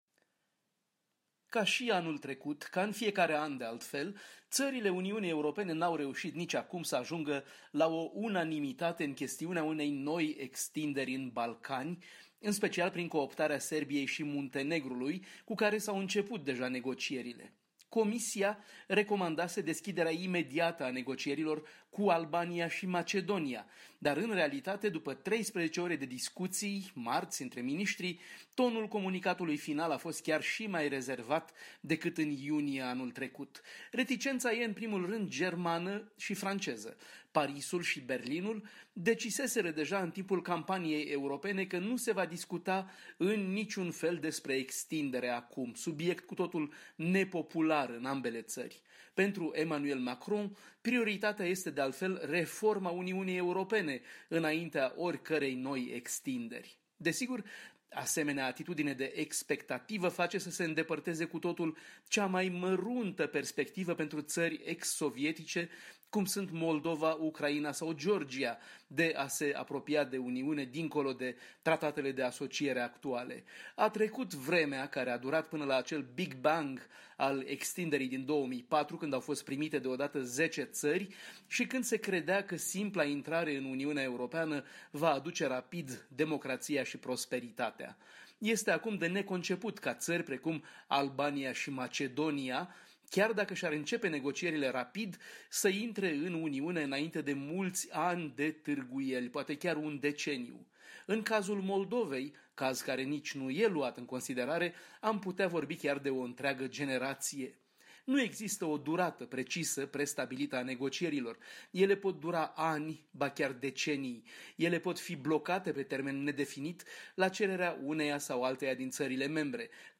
Corespondența zilei de la Bruxelles